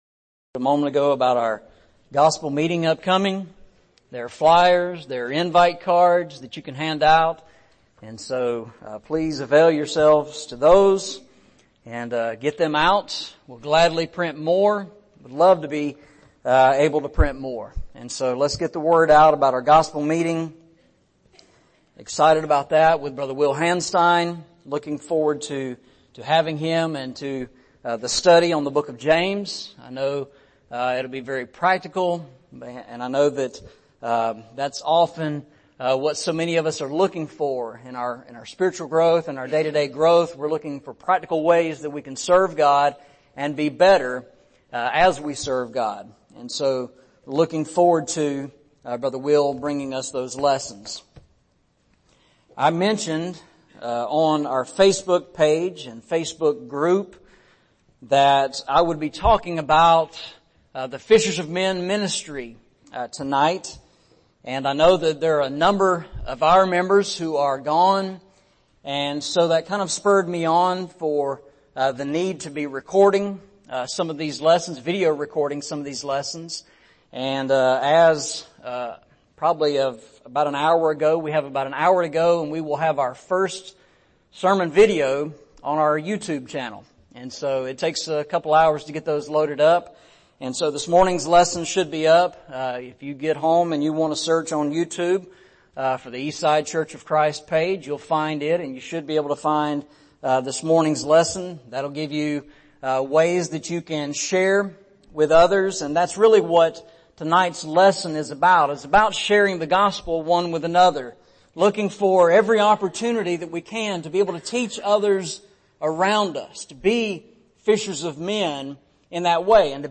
Series: Eastside Sermons